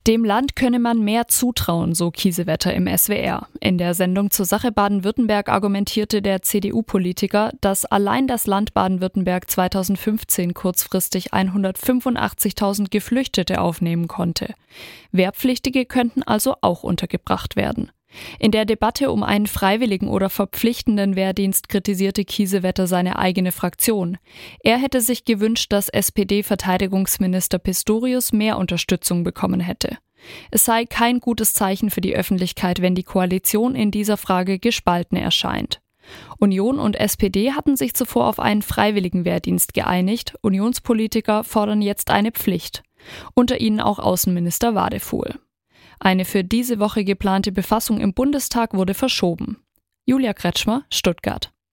Gegner argumentieren oft, dass die Bundeswehr die Wehrpflicht rein logistisch gar nicht stemmen könne - das sieht CDU-Verteidigungspolitiker und Bundeswehr-Oberst a.D. Roderich Kiesewetter (Bundestagswahlkreis Aalen-Heidenheim) anders. In der Sendung "Zur Sache! Baden-Württemberg" sagte er, dass allein Baden-Württemberg 2015 kurzfristig 185.000 Menschen aufnehmen konnte, die vor dem Krieg in Syrien geflohen waren.